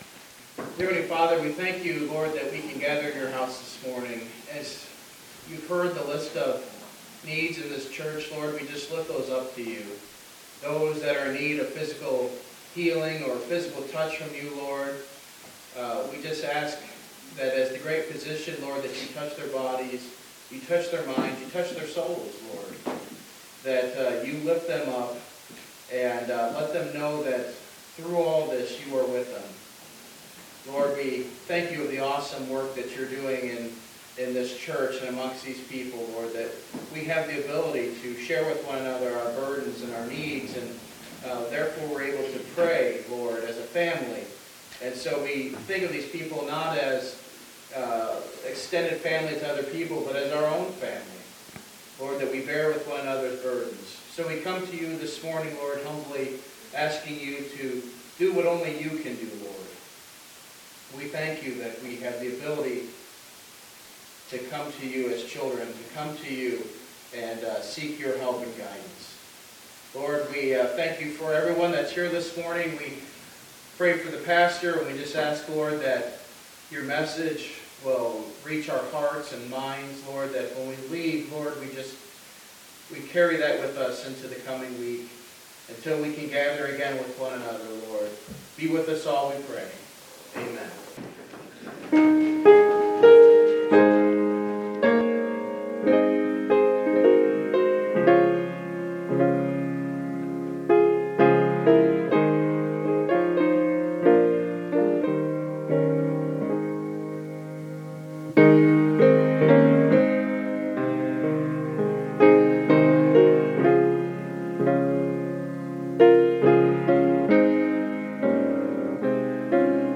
Topical Sermons